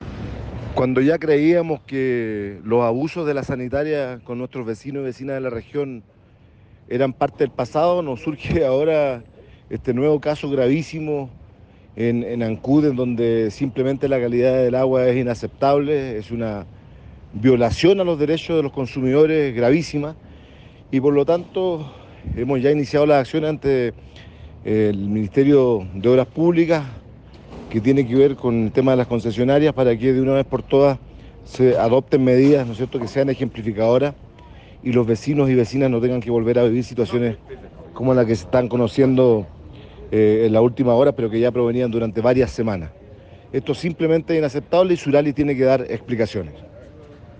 A su vez, en la cámara alta, el senador Fidel Espinoza informó que ofició al seremi de Obras Públicas, Fernando Alvarado, requiriendo que pueda remitir antecedentes sobre la denuncia de varios dirigentes de Ancud, sobre la contaminación que se ha venido registrando por varias semanas y meses en la distribución del agua potable.